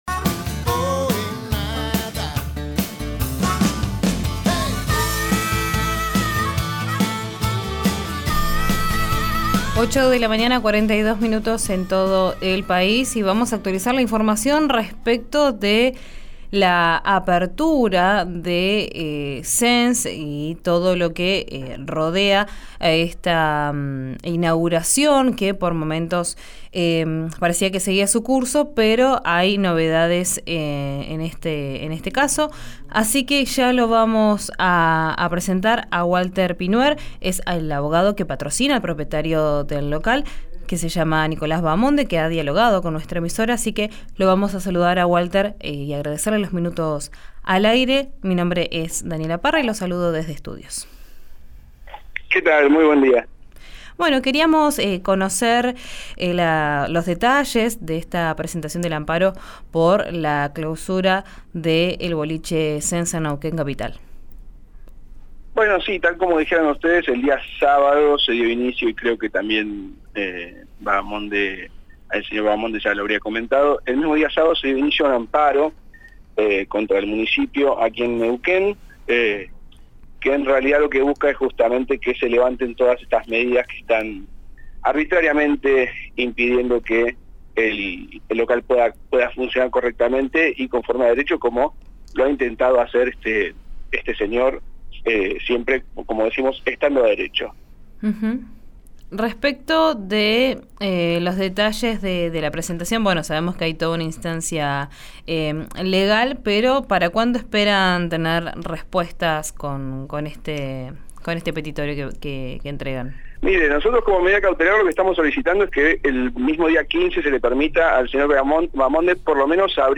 habló en RÍO NEGRO RADIO acerca del recurso de amparo que solicitaron para abrir este fin de semana.